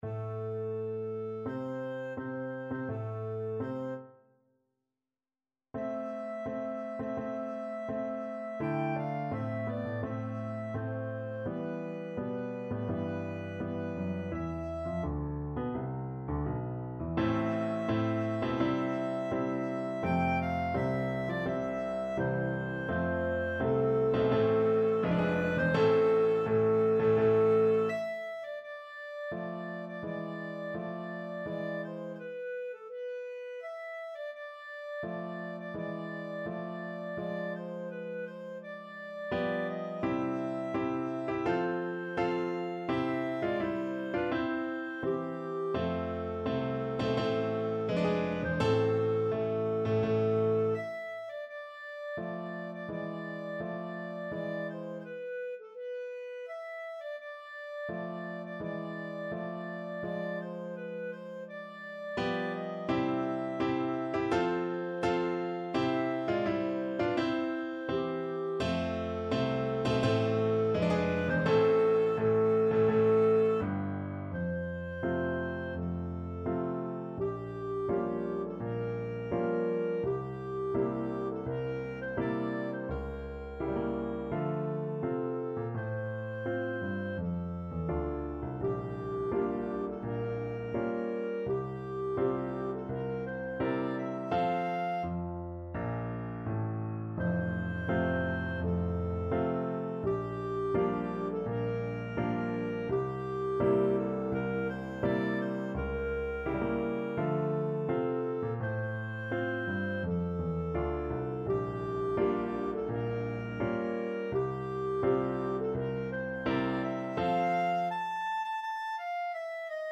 Free Sheet music for Clarinet
4/4 (View more 4/4 Music)
Tempo di Marcia =84
A minor (Sounding Pitch) B minor (Clarinet in Bb) (View more A minor Music for Clarinet )
Classical (View more Classical Clarinet Music)